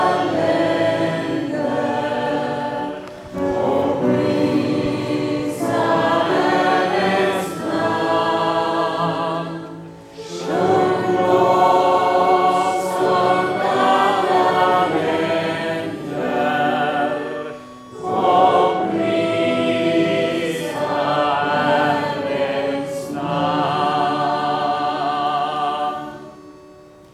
lovsångsafton med ett lovsångsteam med deltagare från de olika länderna.
sjung-lovsang.mp3